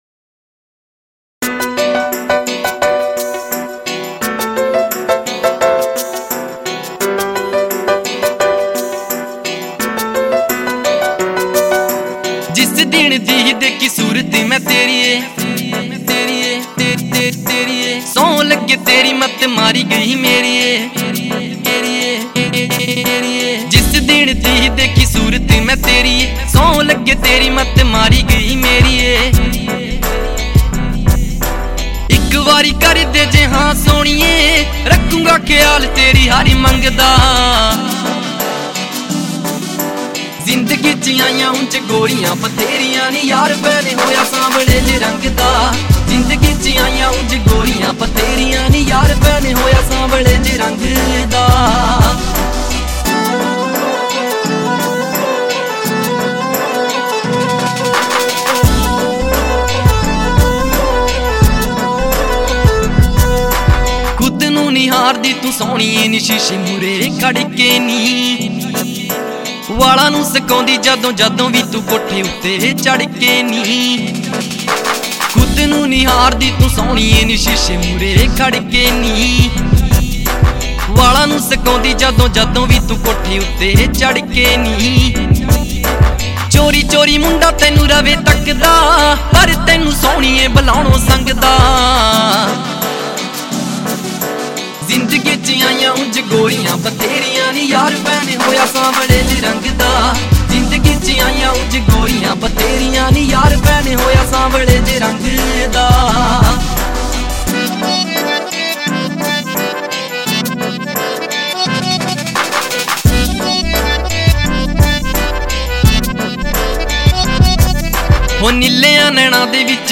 Song Genre : Punjabi Old Song